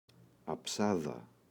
αψάδα, η [a’psaða]